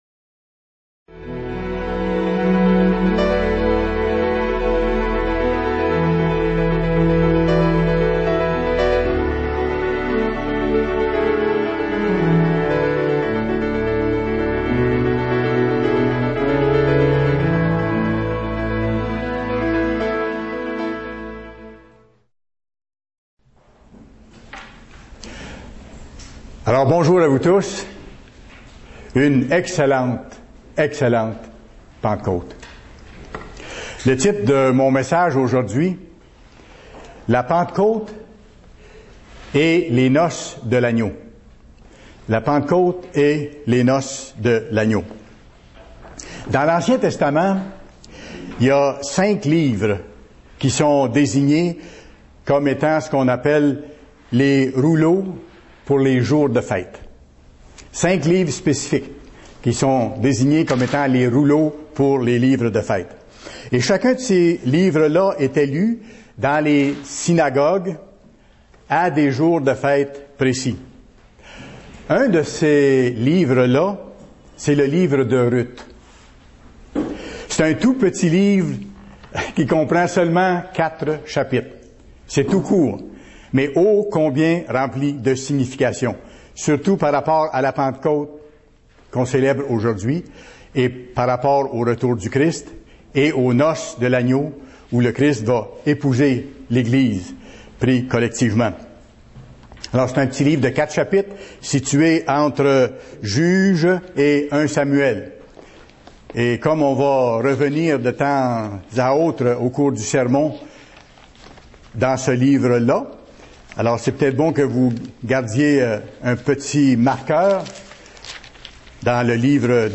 Fête de la Pentecôte